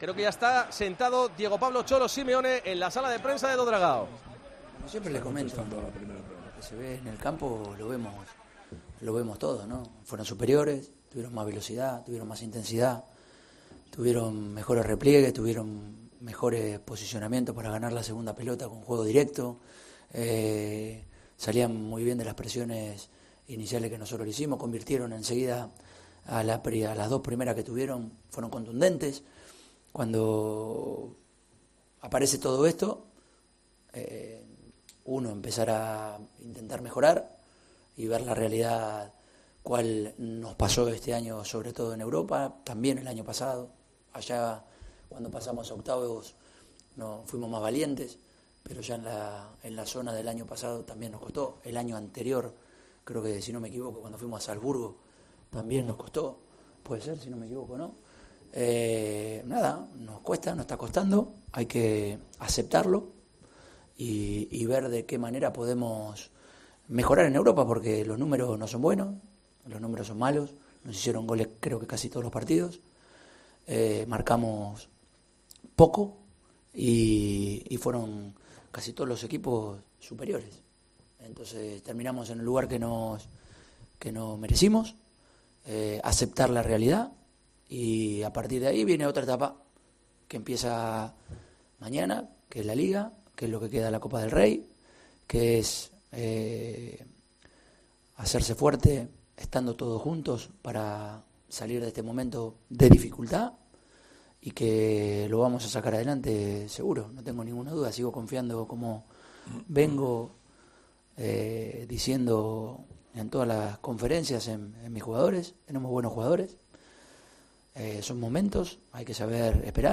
El 'Cholo' reconoció en la rueda de prensa posterior al partido frente al Oporto en O Dragao que el equipo portuense "